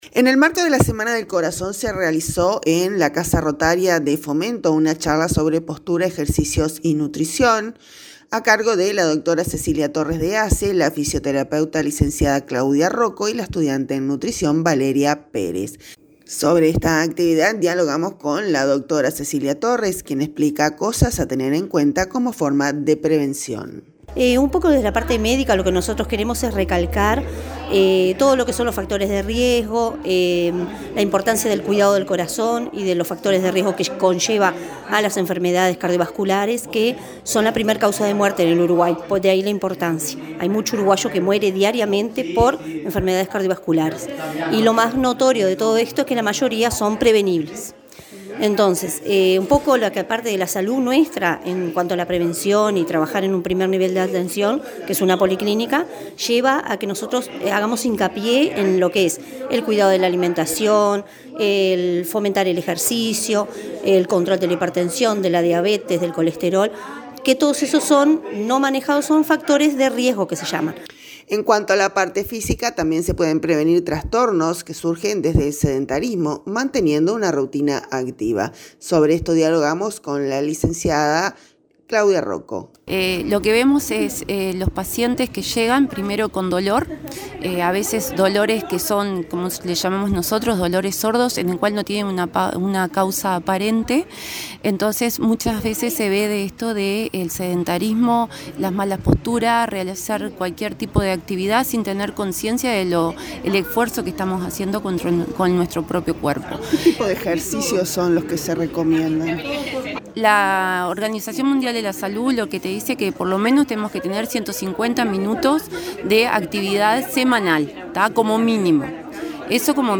Sobre esta actividad, dialogamos